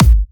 VEC3 Bassdrums Trance 45.wav